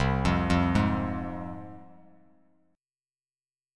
Csus4#5 chord